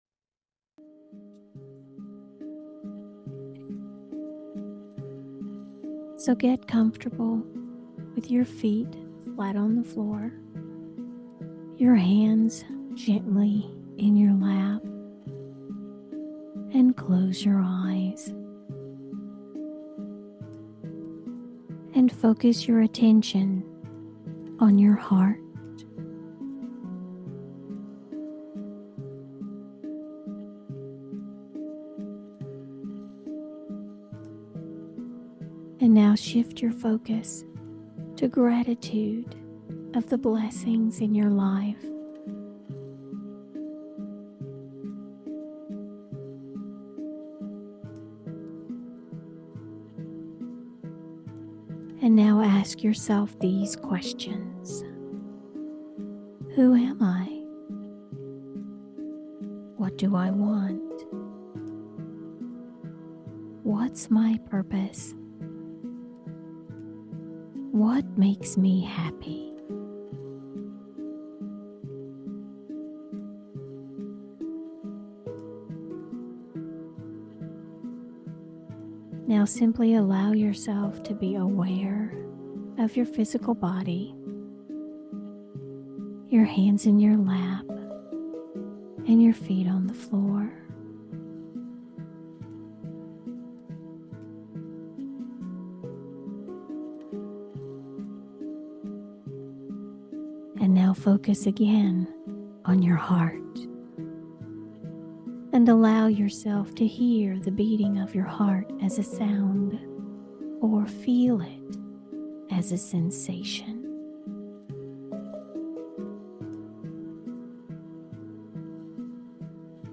The Quiet Your Mind SHORT guided experience supports you to bring your heart and mind into better harmony/coherence – opening the way for you to more easily sync with your superconscious/higher-self – the divinity within – to use the power within you.